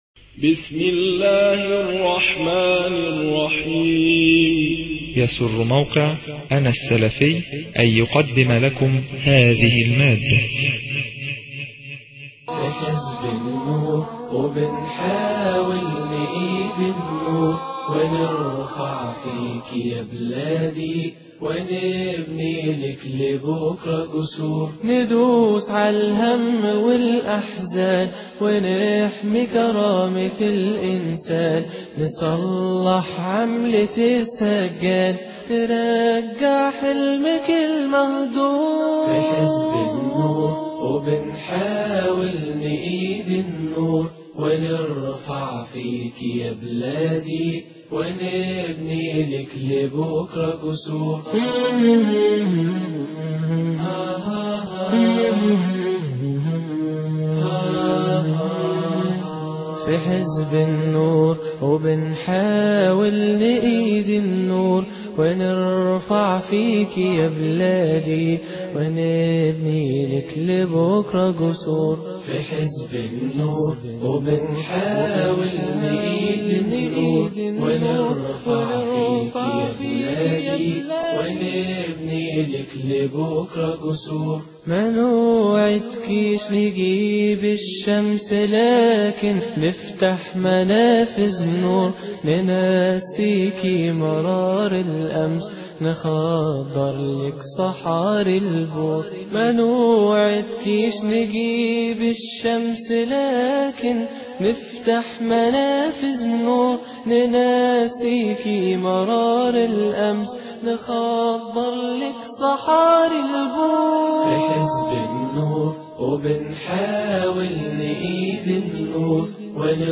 مؤتمر حزب النور بالنهضة - الشيخ عبد المنعم الشحات